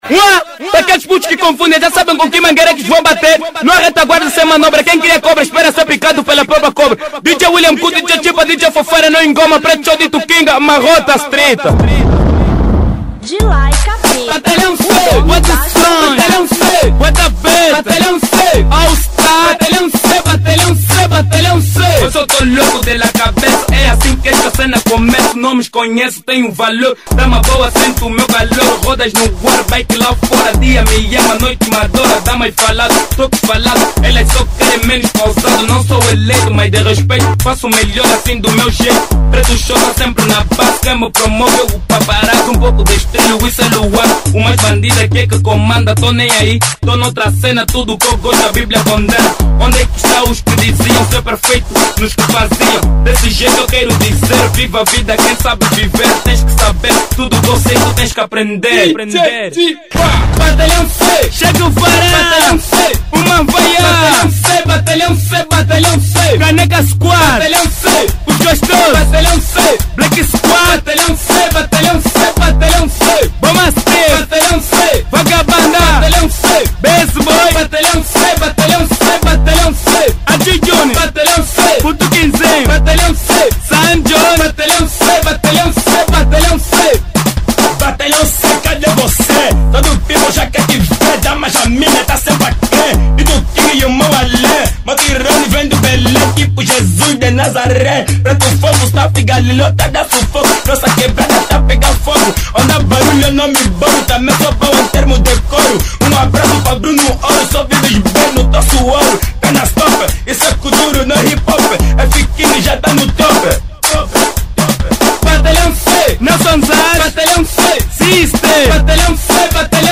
Kuduro 2007